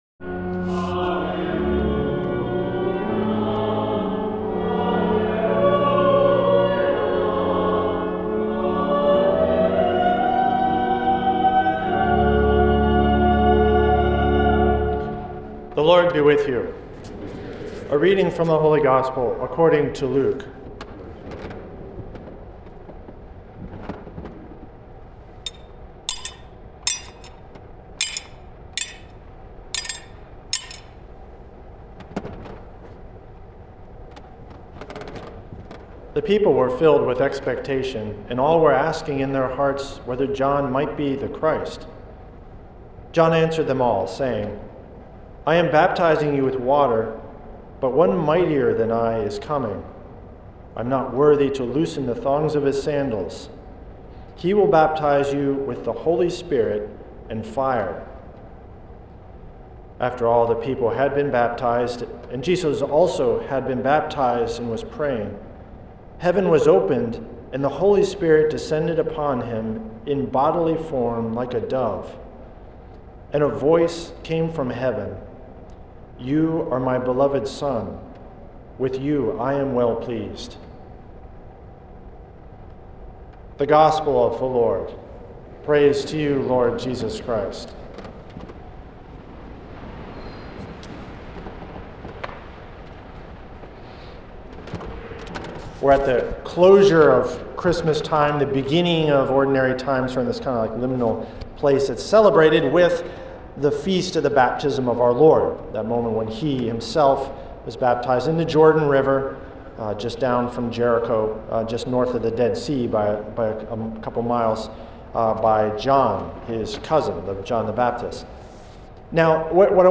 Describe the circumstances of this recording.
at St. Patrick’s Old Cathedral in NYC on January 12th, 2025.